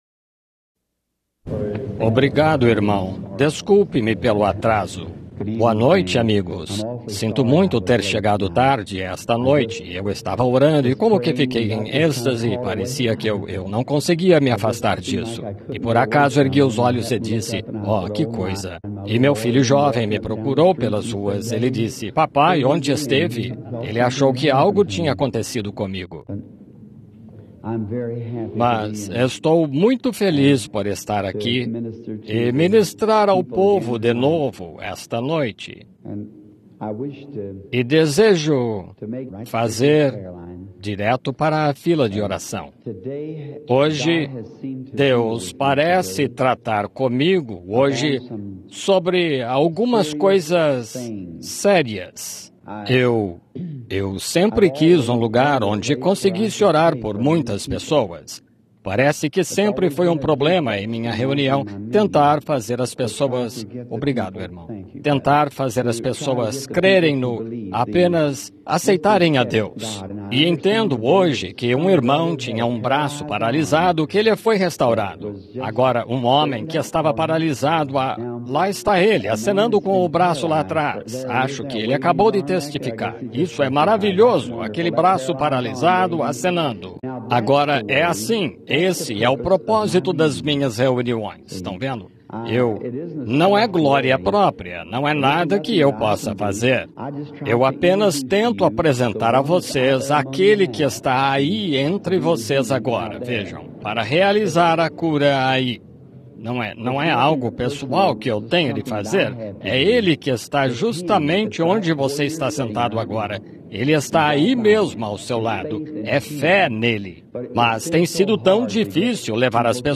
COMO FUI COM MOISÉS 03 de Maio de 1951 Los Angeles – Califórnia – E.U.A. Tradução: Gravações A Voz de Deus [EUA]